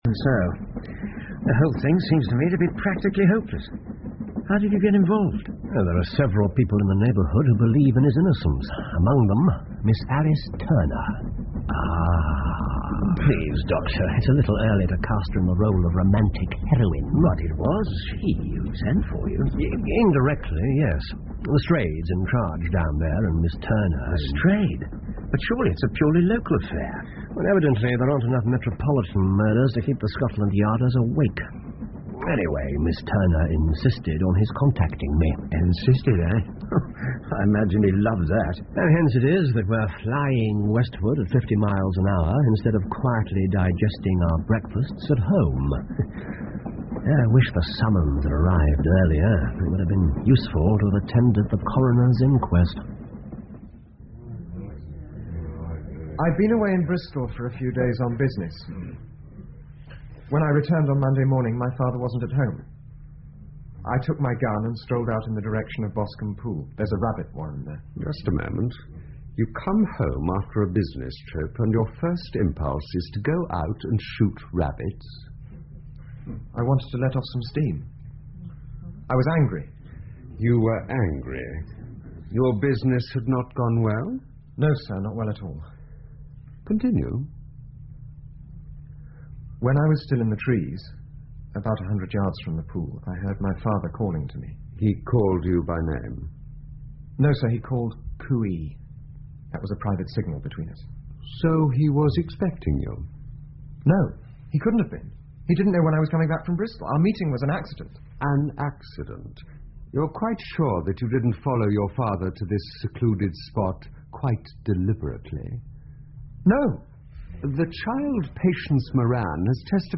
福尔摩斯广播剧 The Boscombe Valley Mystery 2 听力文件下载—在线英语听力室